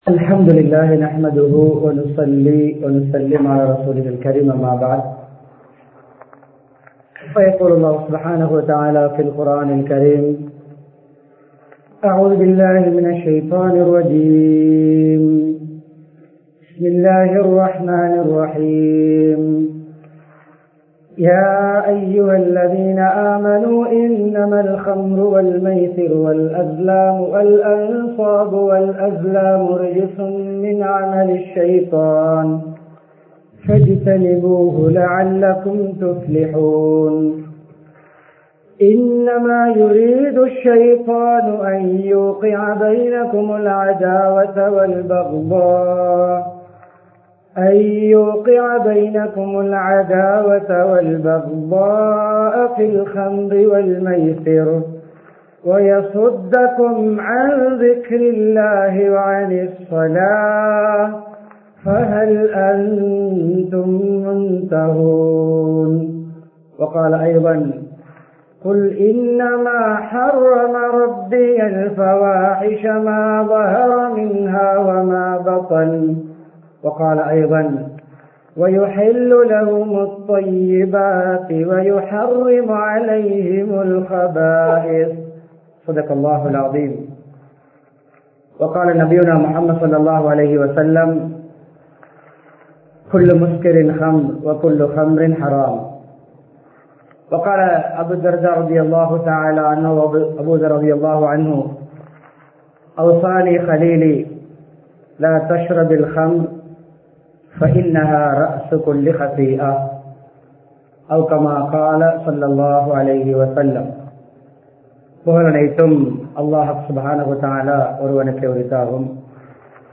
போதையற்ற சமூகத்தை உருவாக்குவோம் | Audio Bayans | All Ceylon Muslim Youth Community | Addalaichenai
Muhiyadeen Jumua Masjith